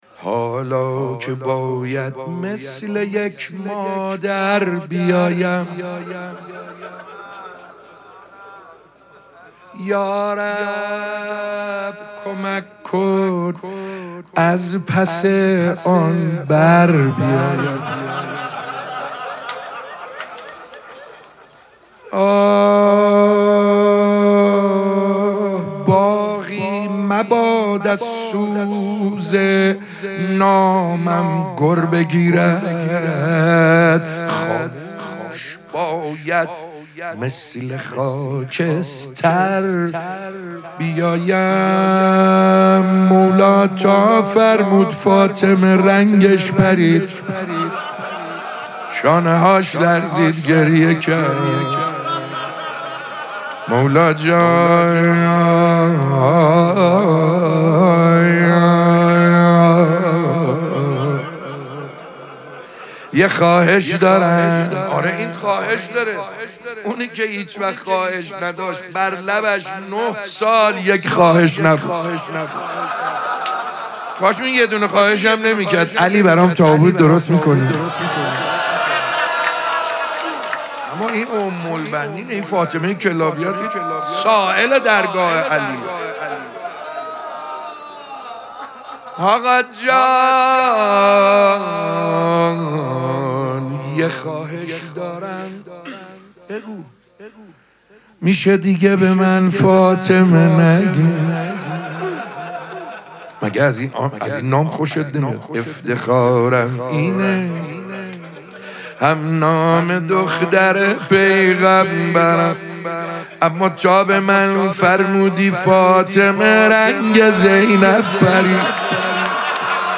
سبک اثــر روضه مداح حاج سعید حدادیان